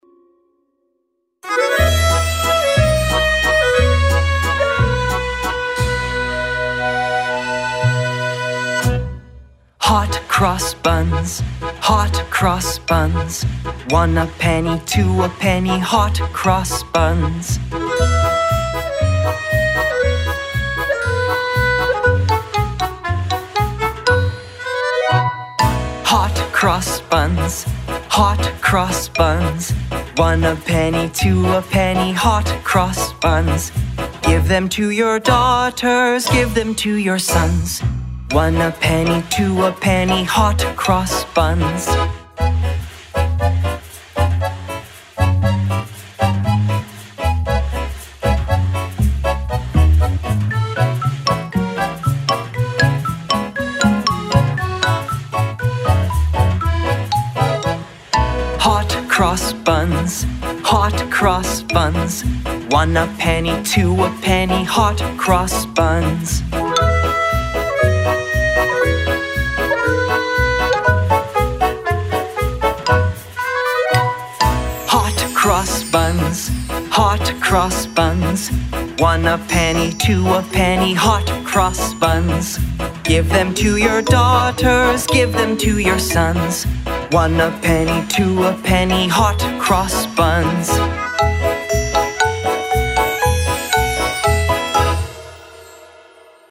Hot Cross Buns – an old children’s song